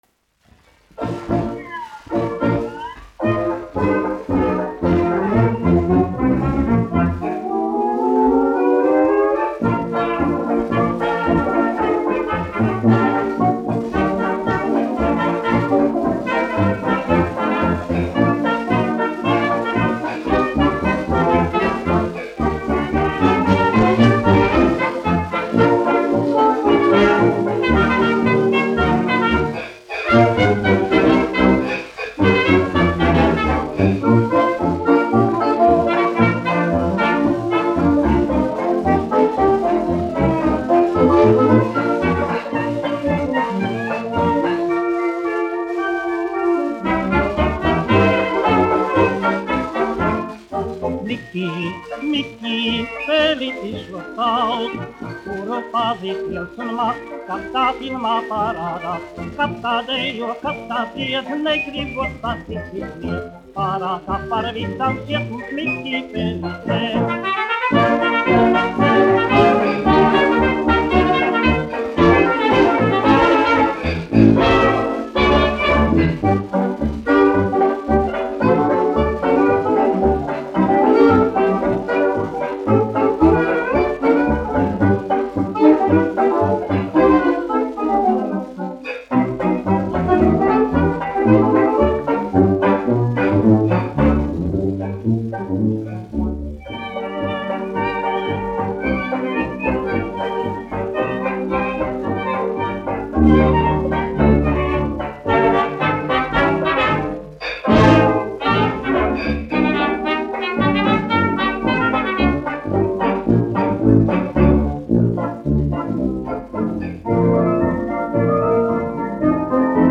1 skpl. : analogs, 78 apgr/min, mono ; 25 cm
Kinomūzika
Fokstroti
Skaņuplate